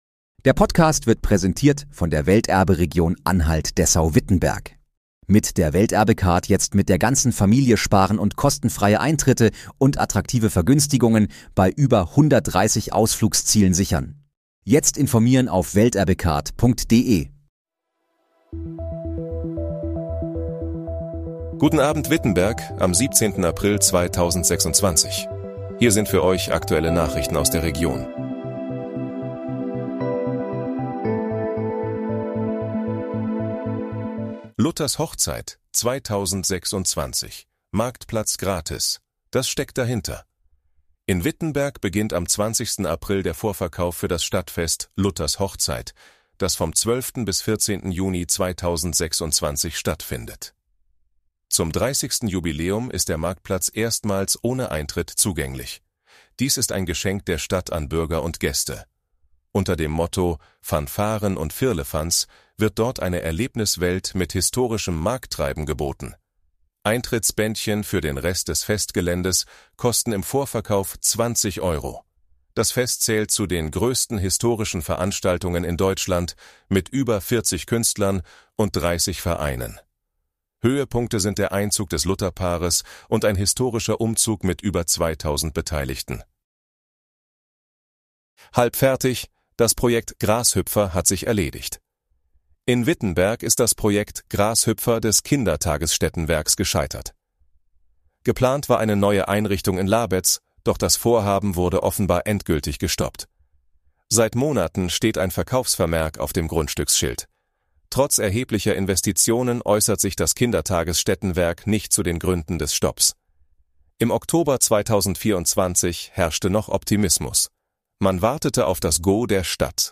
Guten Abend, Wittenberg: Aktuelle Nachrichten vom 17.04.2026, erstellt mit KI-Unterstützung